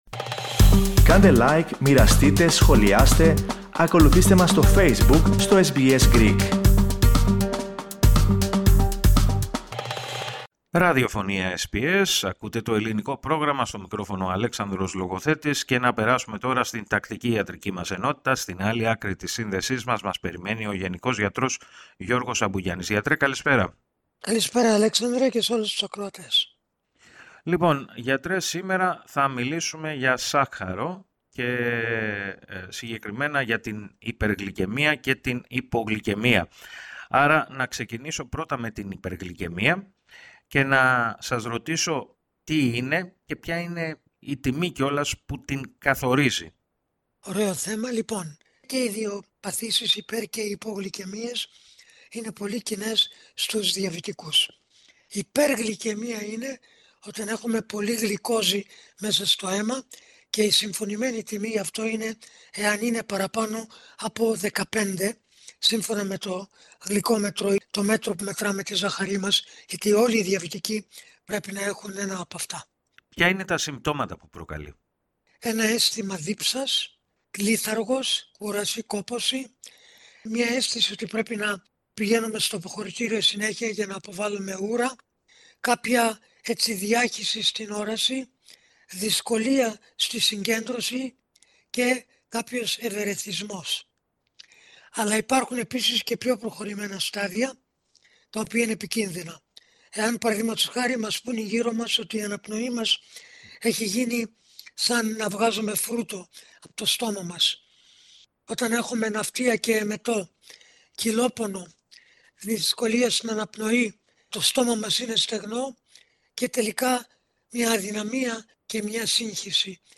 Ο γενικός γιατρός